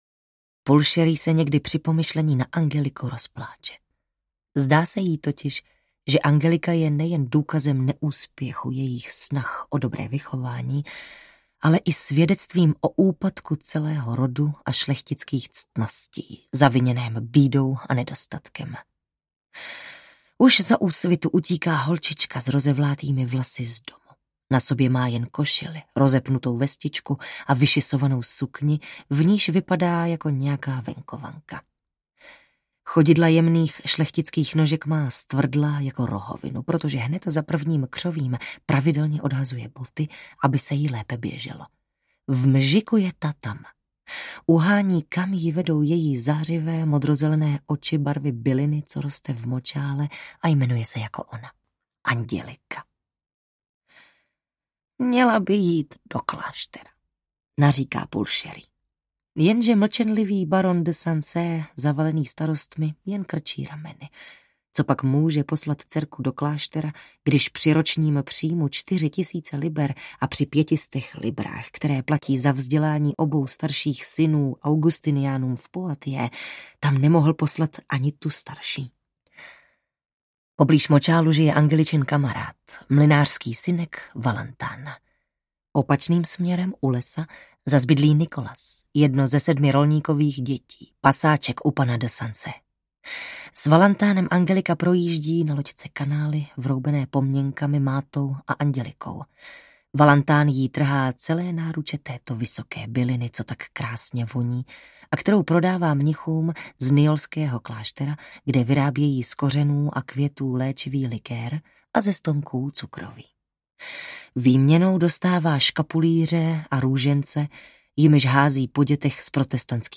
Ukázka z knihy
angelika-markyza-andelu-audiokniha